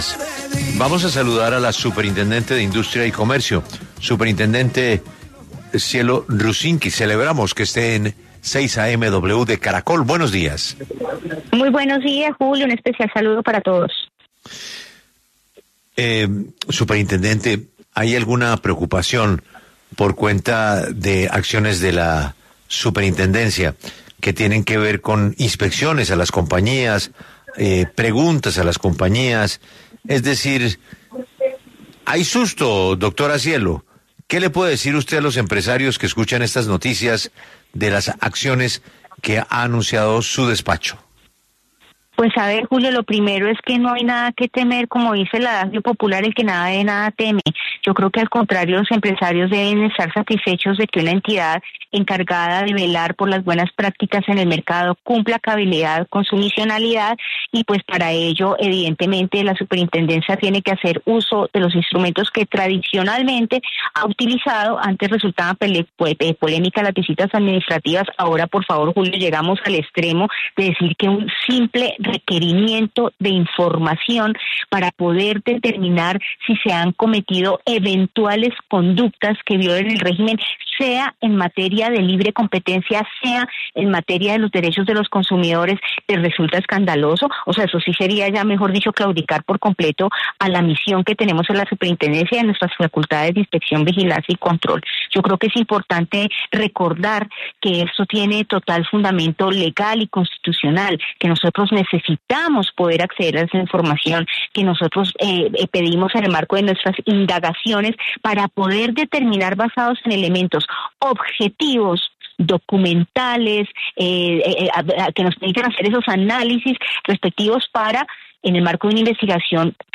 Debate: Superindustria Rusinque responde a Fenalco por solicitar información de fijación de precios
La Superintendente de Industria y Comercio, Cielo Rusinque, habló en 6AM W sobre los requerimientos que reveló esta emisora a las empresas pidiendo información sobre la fijación de precios, utilidades y el impacto del cambio del dólar. Anunció que van a pedirle información a más de 50 empresas.